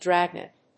音節drág・nèt 発音記号・読み方
/ˈdræˌgnɛt(米国英語), ˈdræˌgnet(英国英語)/